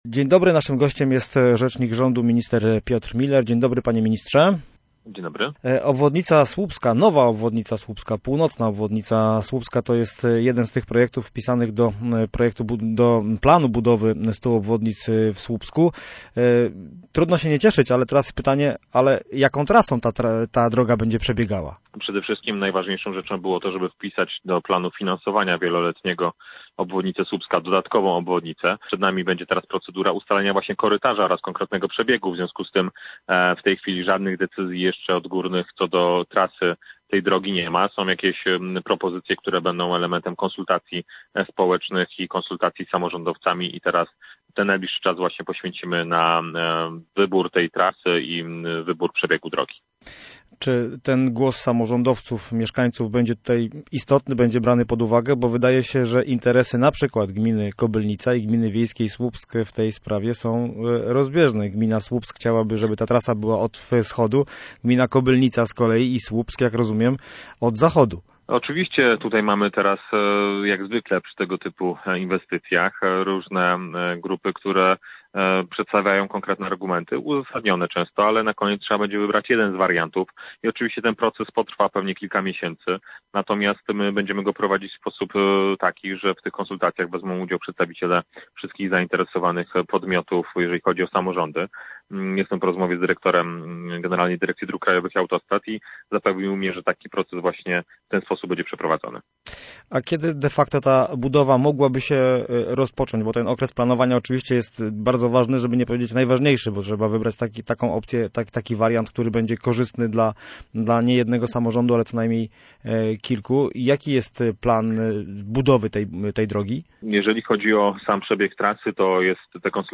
Piotr Müller był gościem miejskiego programu Radia Gdańsk Studio Słupsk 102 FM.